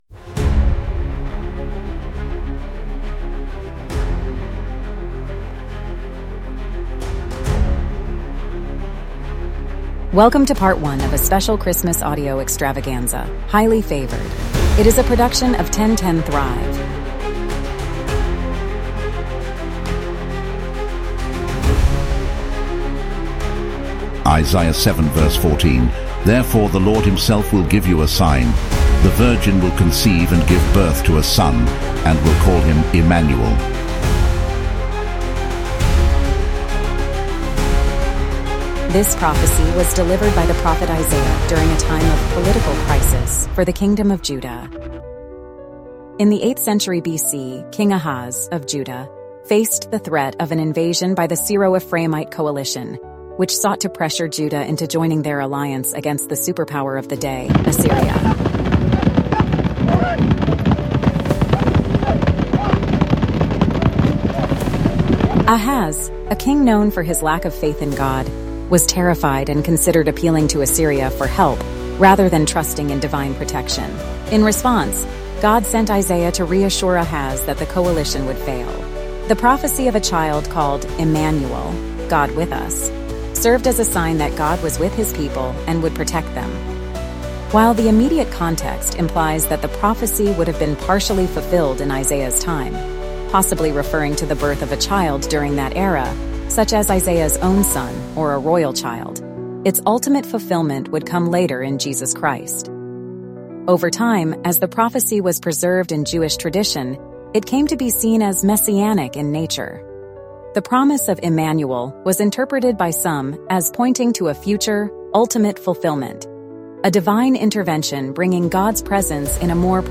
Against the backdrop of Roman rule and Herod the Great's tyrannical reign, the music and dialogue capture the desperation and faith of a people yearning for deliverance. The narrative transitions to the small Galilean village of Nazareth, where we meet Joachim and Anne, a humble and devout couple longing for a child.